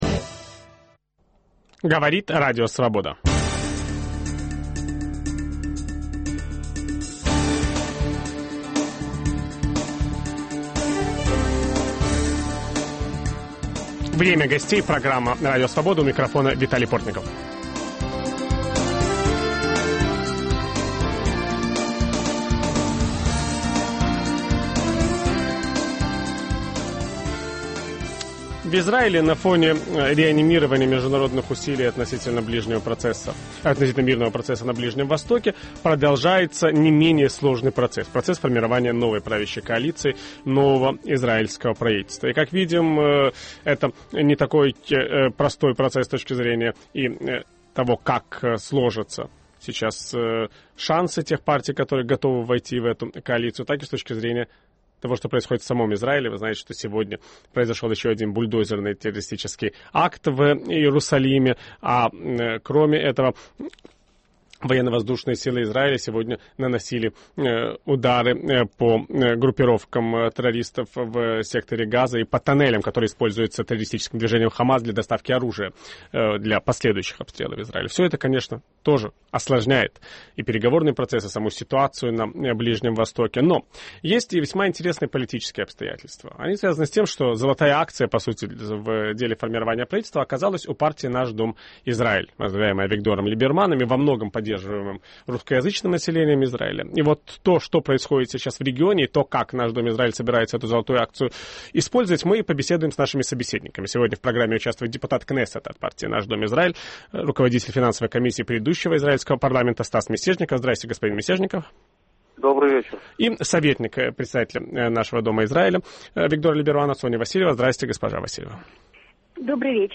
Об этом ведущий программы Виталий Портников беседует с депутатом израильского парламента от партии "Наш дом Израиль" Стасом Мисежниковым.